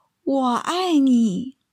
Wǒ ài nǐ
ウォ アイ ニー
第四声なので、きつい印象になるくらいに上から一気に音を下げます。